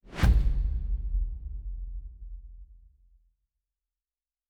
Fantasy Interface
Special Click 37.wav